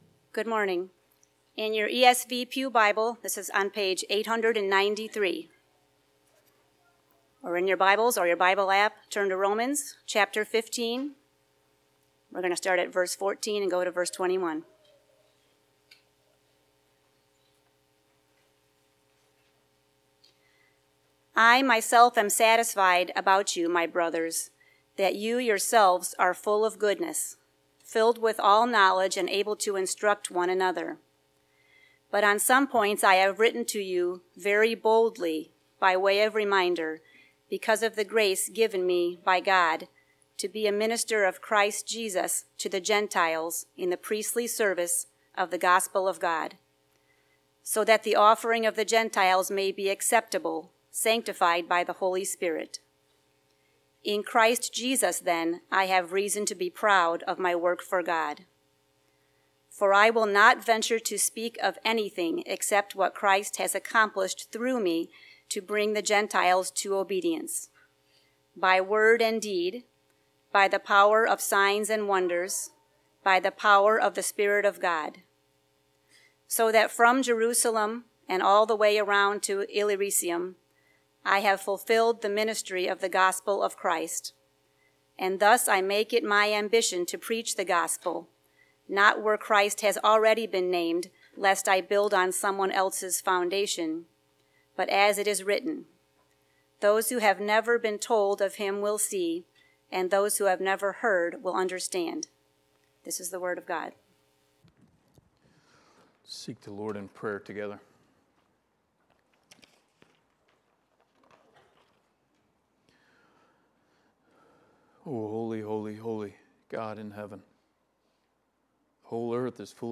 Passage: Romans 15:14-21 Service Type: Sunday Morning